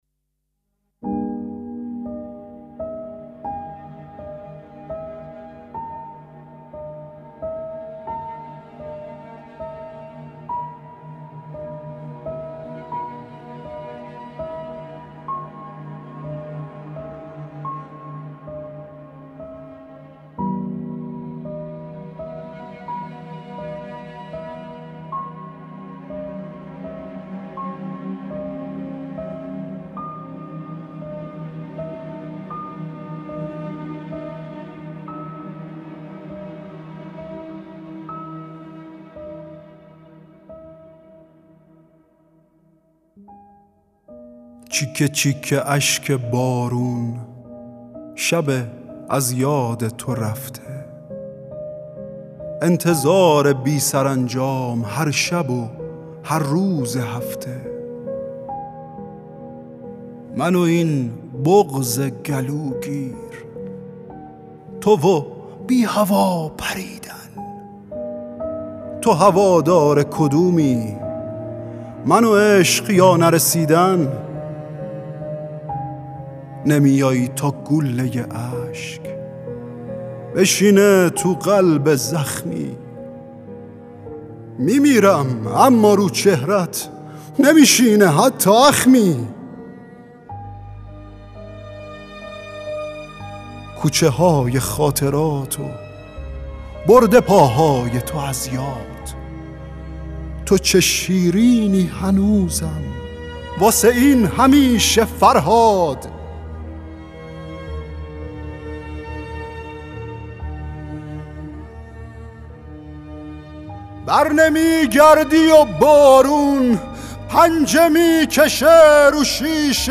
موقعیتِ سرد(دکلمه
دکلمه عاشقانه